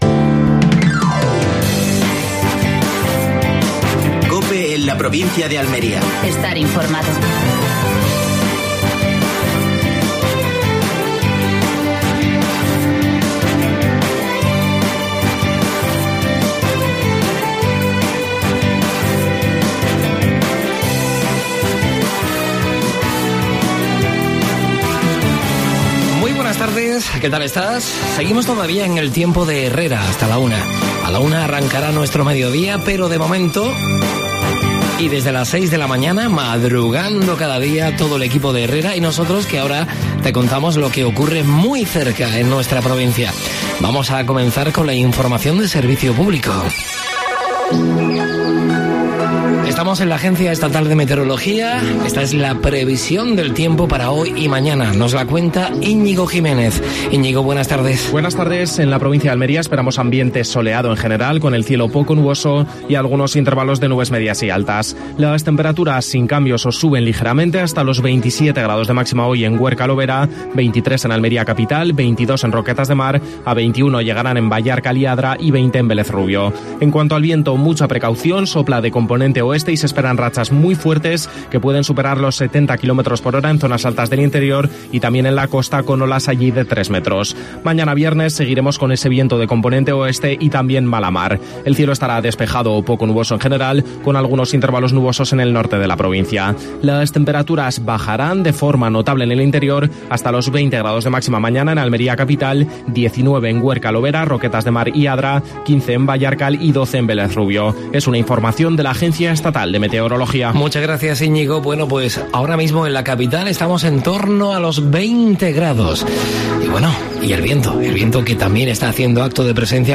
Última hora en Almería. Entrevista a Paola Laynez (concejala del Ayuntamiento de Almería).